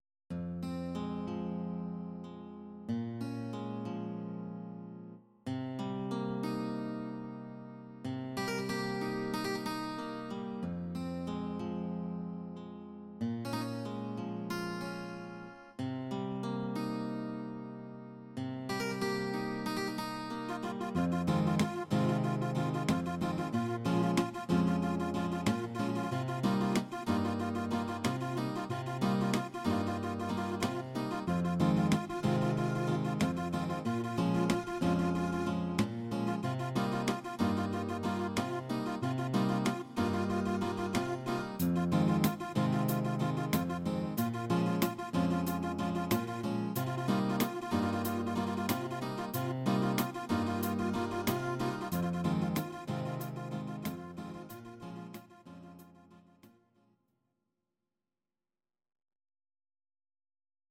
Audio Recordings based on Midi-files Style: Ital/French/Span